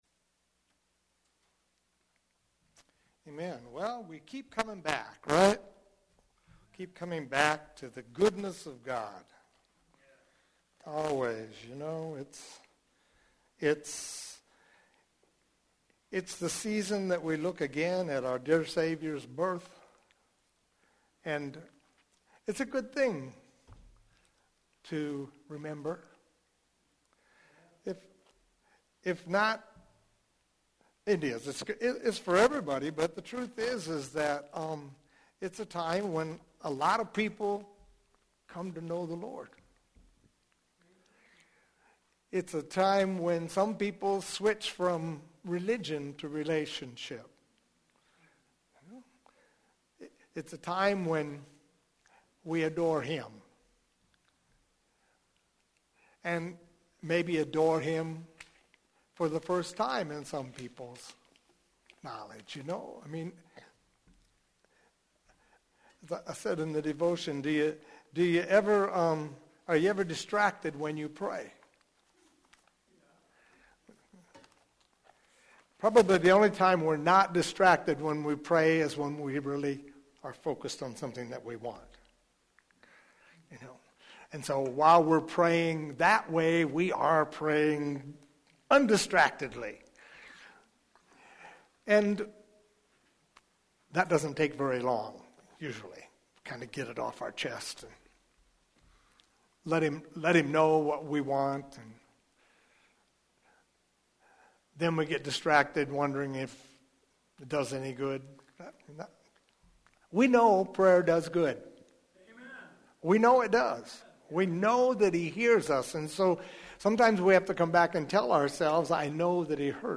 Here you’ll find a selection of audio recordings from Hosanna Restoration Church.